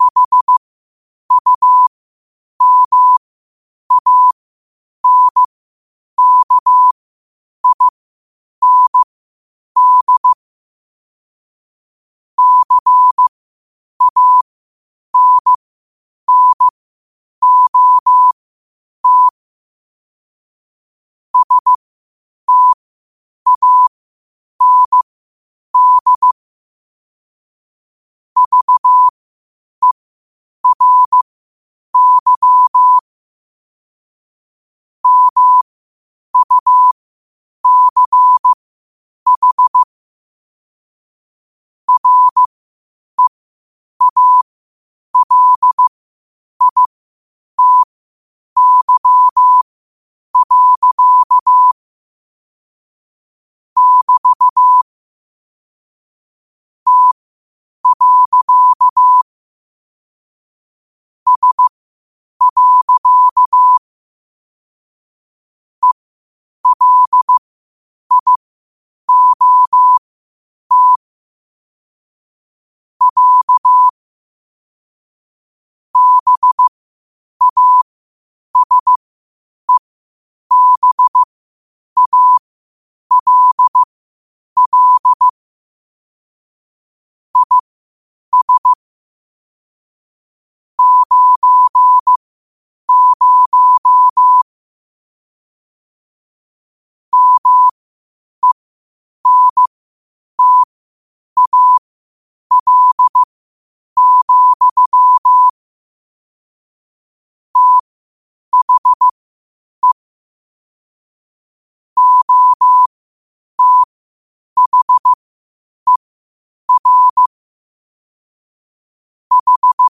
Quotes for Thu, 14 Aug 2025 in Morse Code at 8 words per minute.